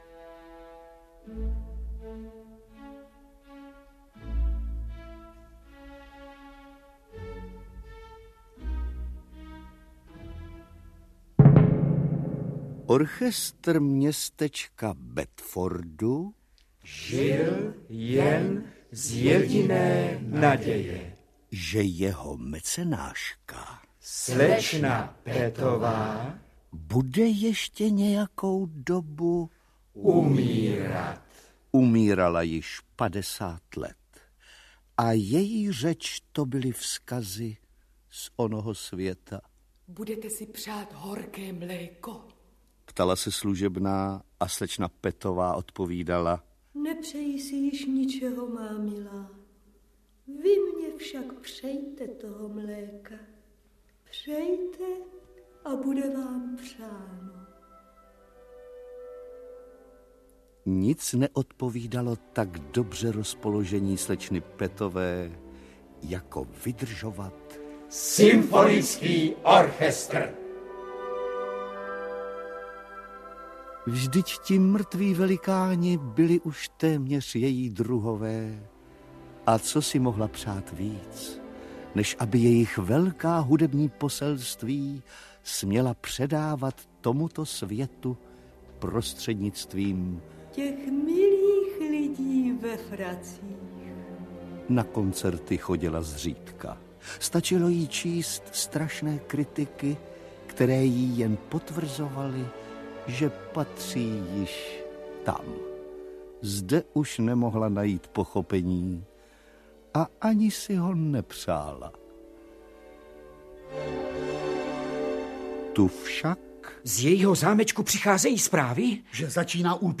Dramatizace povídky ze sbírky Muzikální Sherlock, ve které houslista Sherlock Holmes s orchestrem studuje Haydnovu symfonii S úderem kotlů. V roli Sherlocka Holmese Josef Kemr.
Ukázka z knihy